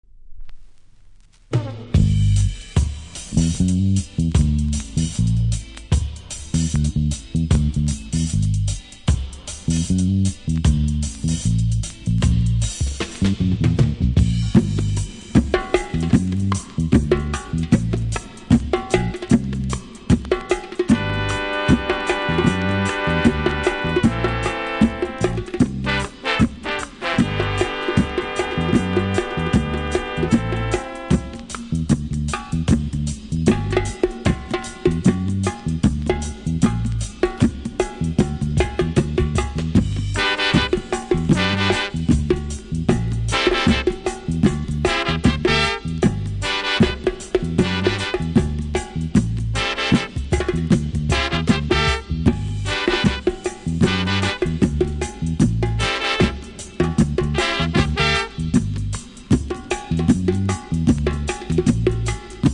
ホーンとボンゴのインストも良いのでチェックしてみて下さい。
両面ジュークボックス使用キズがあり見た目悪いですが音はそれほど影響されていませんので試聴で確認下さい。